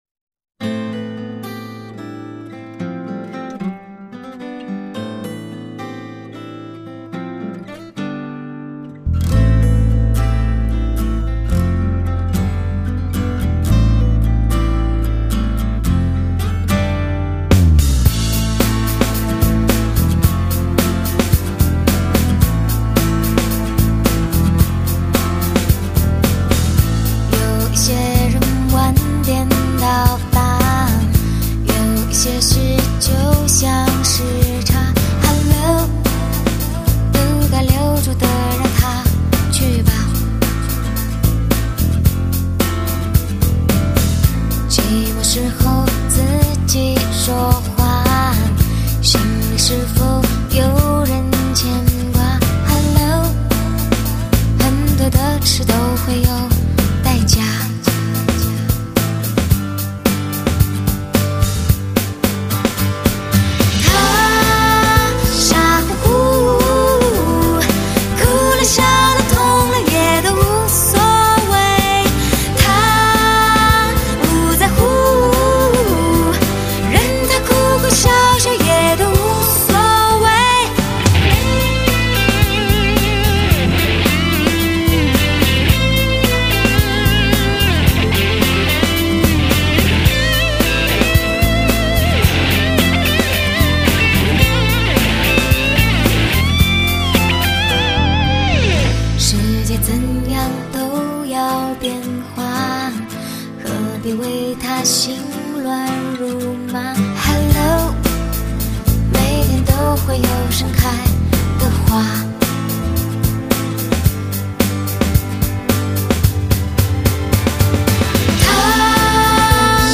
华语流行